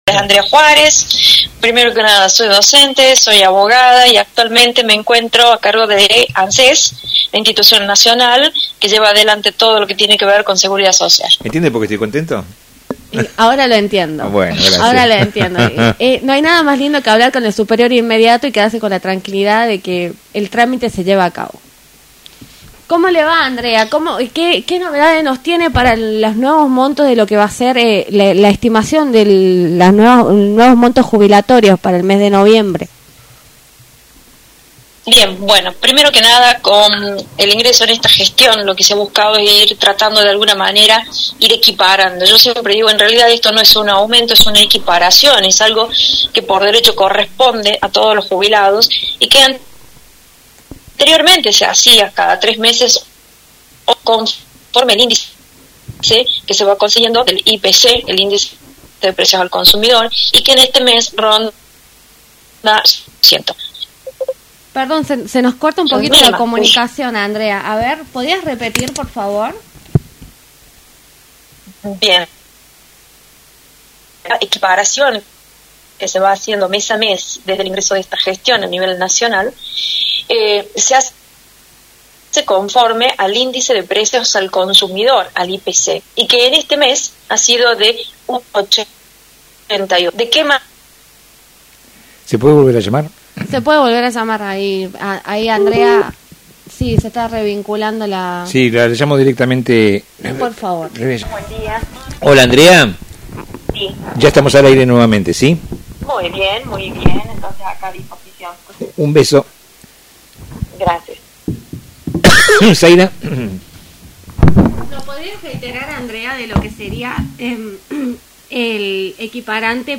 En diálogo con NUEVA ÉPOCA, por Radio Libertad